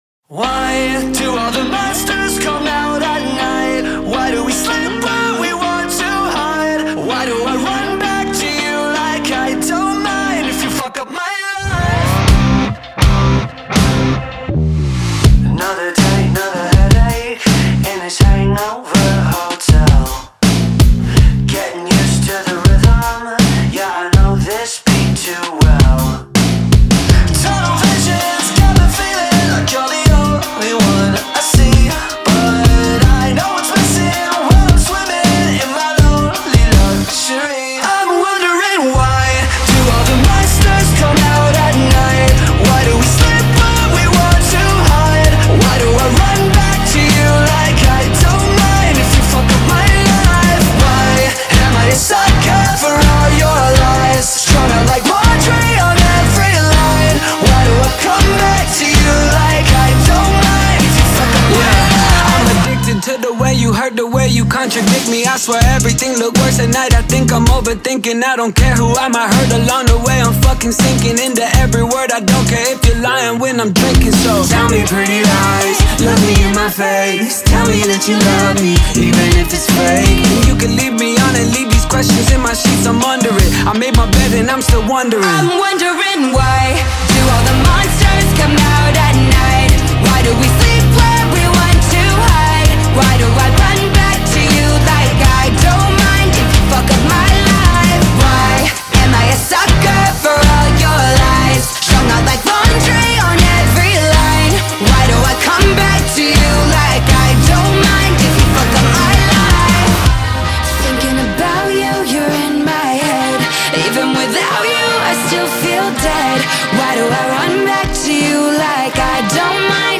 BPM81-81
Audio QualityPerfect (High Quality)
Pop song for StepMania, ITGmania, Project Outfox
Full Length Song (not arcade length cut)